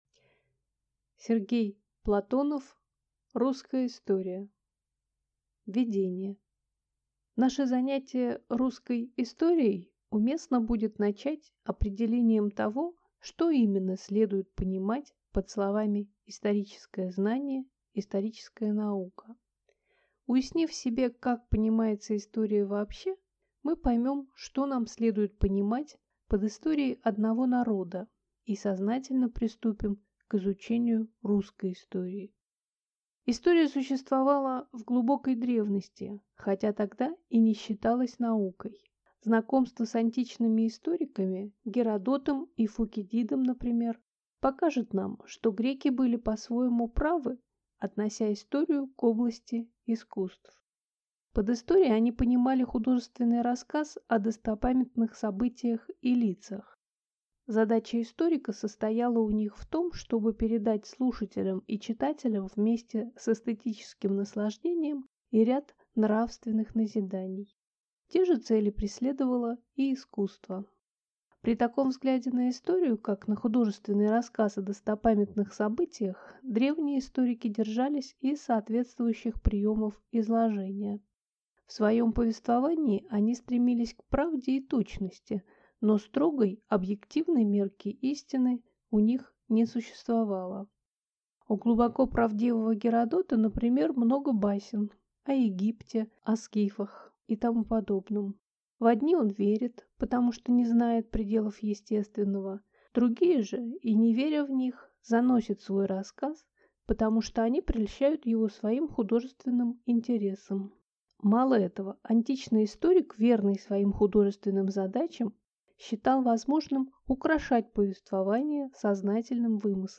Аудиокнига Русская история | Библиотека аудиокниг